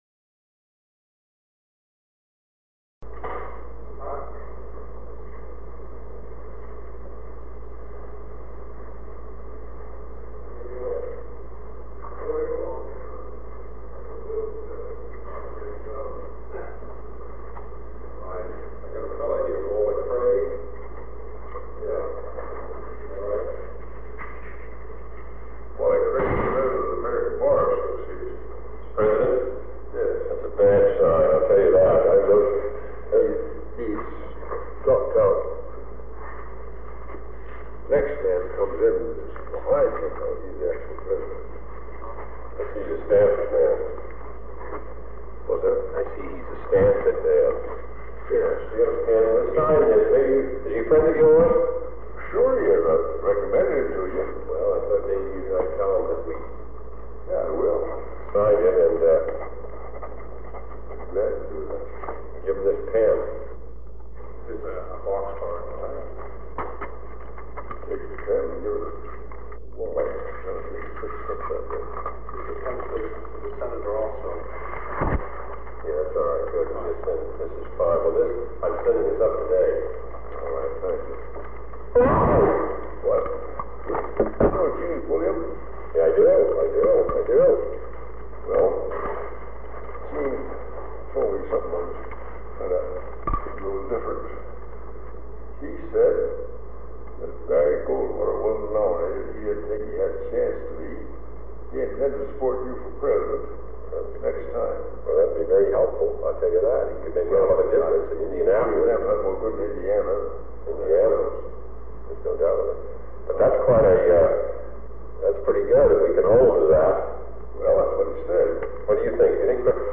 Sound recording of a meeting held on August 23, 1963, between President John F. Kennedy and Senator Carl Hayden of Arizona. They discuss the 1964 political campaign, specifically Senator Barry Goldwater of Arizona, and water issues in Arizona. After the meeting ends, there is a brief discussion between President Kennedy and a staff member about getting something into a newspaper.